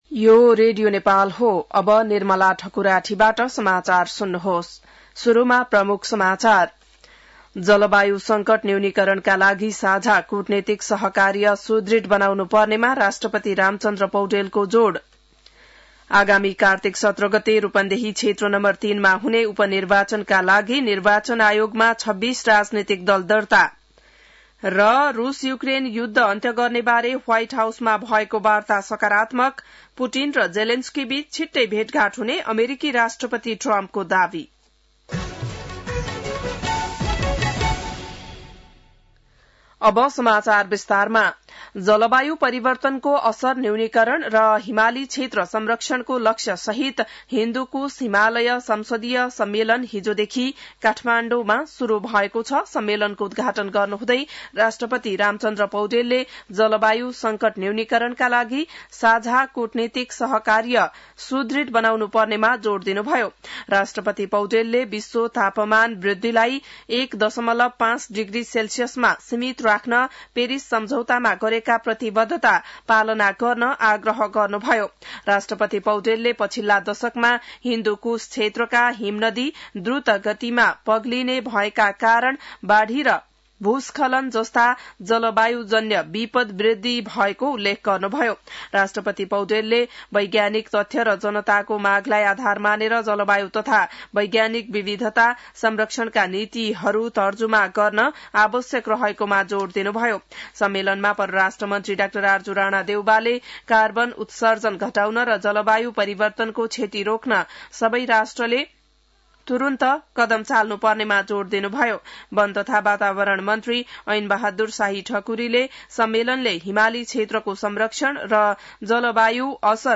बिहान ९ बजेको नेपाली समाचार : ३ भदौ , २०८२